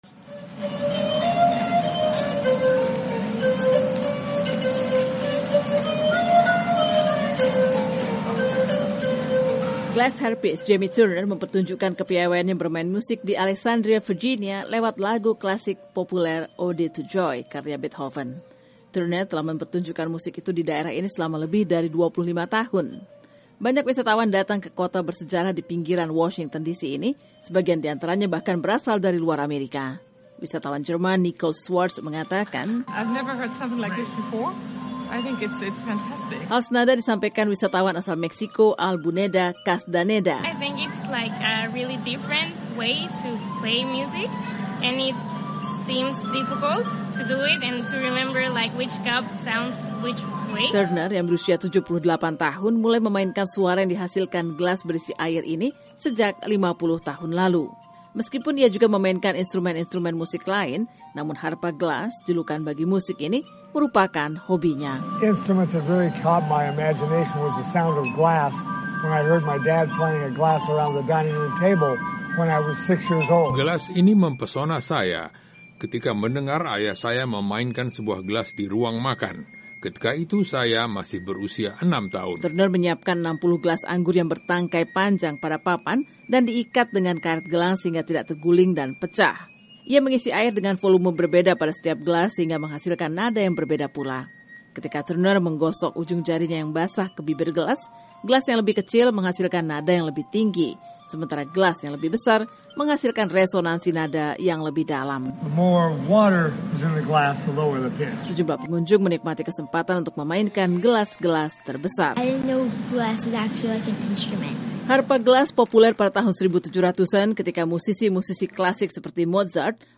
Ia memainkan musik dengan menggunakan gelas-gelas anggur yang berisi air. Ia menggunakan ujung jarinya untuk menggosok bibir gelas guna menciptakan berbagai nada musik.